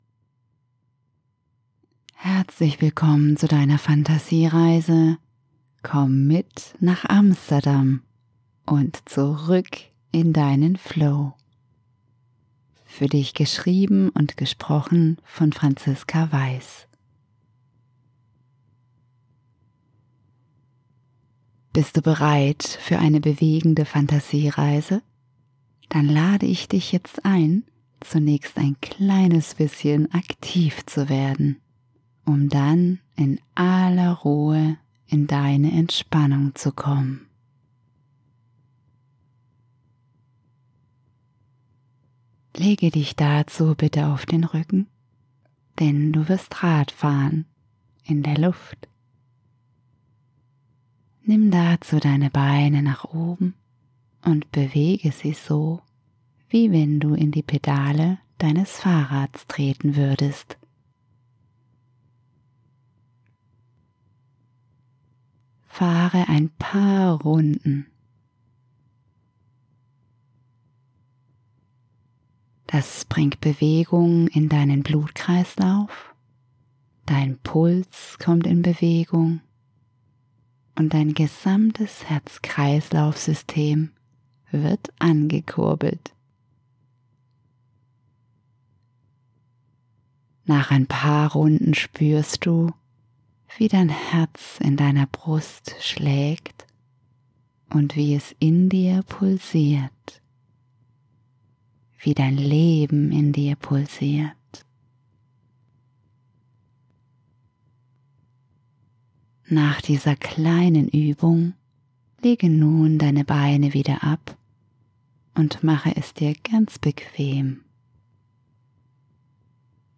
Eine bewegende Fantasiereise
046_Fantasiereise_Amsterdam_ohneMusik.mp3